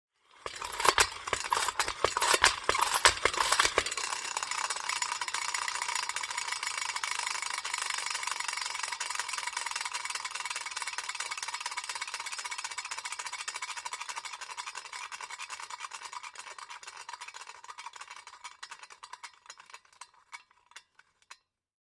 На этой странице собраны звуки юлы — от легкого жужжания до быстрого вращения.
Звук раскручивания детской игрушки юла